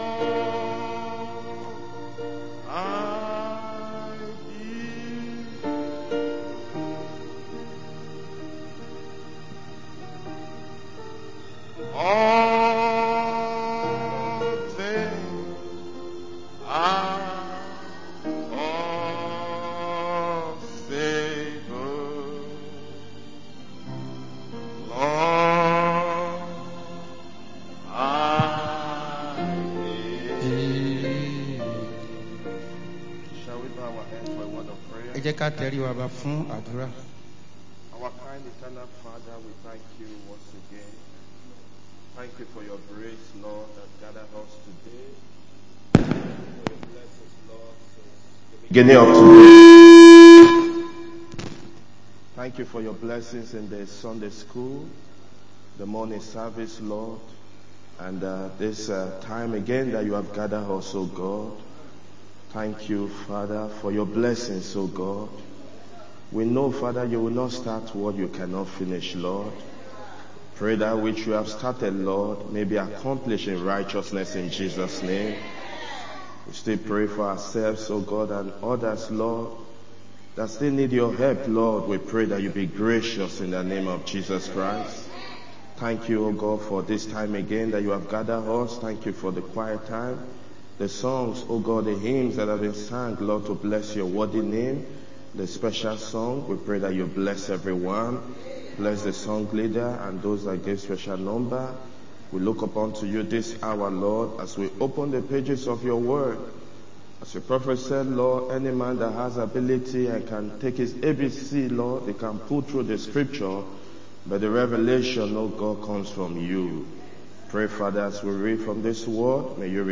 Sunday Afternoon Service 14-09-25